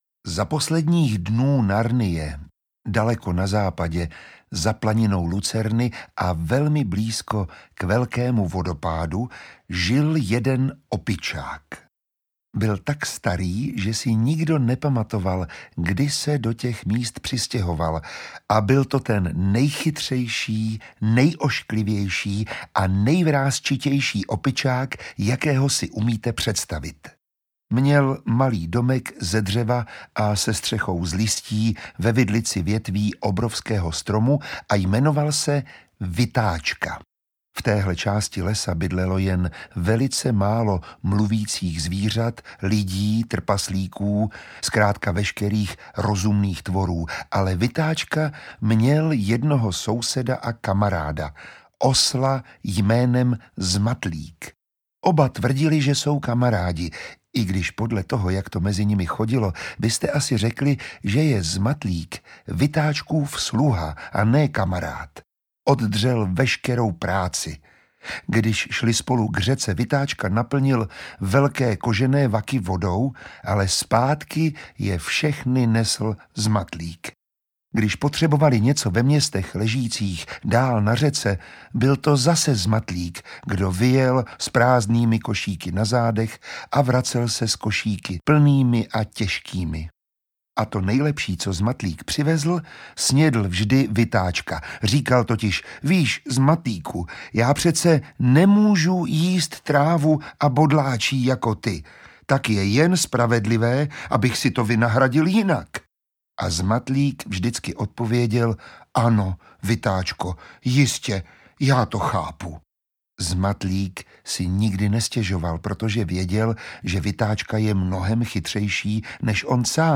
Audio knihaLetopisy Narnie 7 - Poslední bitva
Ukázka z knihy
Začíná bitva, která navždy rozhodne o budoucnosti slavného království Narnie!Nechte se i vy pohltit napínavým příběhem s nečekanými zvraty a zaposlouchejte se do charismatického hlasu Miroslava Táborského, díky němuž před vámi všechny fantastické postavy doslova ožijí.
• InterpretMiroslav Táborský